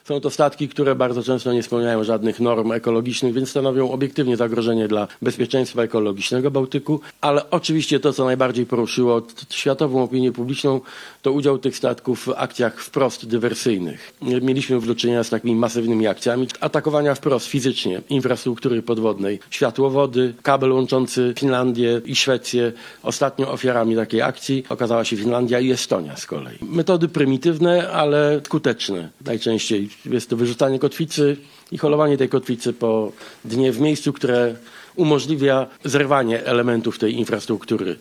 O „flocie cieni” wypowiedział się premier Donald Tusk